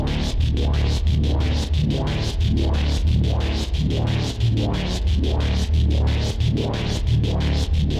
Index of /musicradar/dystopian-drone-samples/Tempo Loops/90bpm
DD_TempoDroneE_90-D.wav